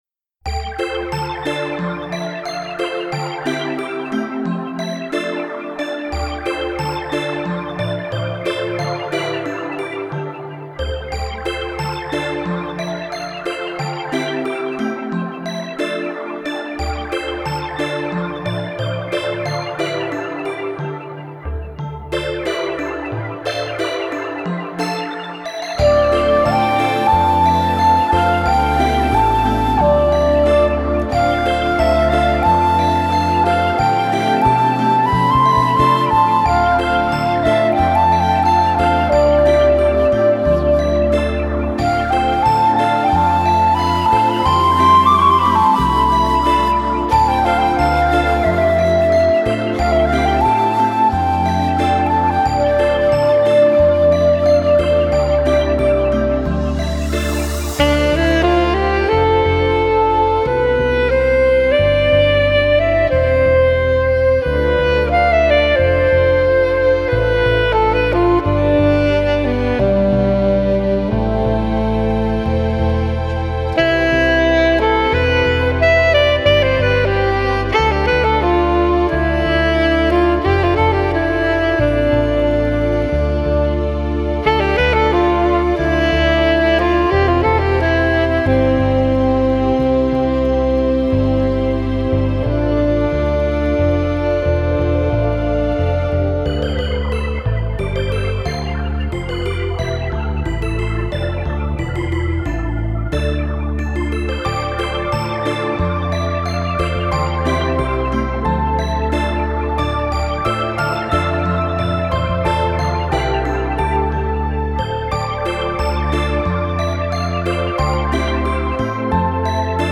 ορχηστρικές συνθέσεις